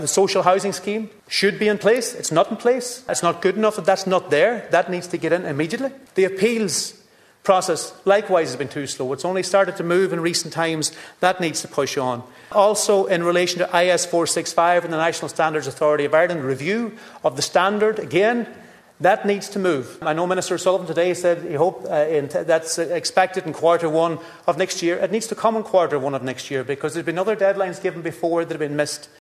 The second stage of the bill introducing amendments to Defective Concrete Block redress legislation were carried out in the Dáil chamber yesterday evening, with Donegal TDs having their voices heard.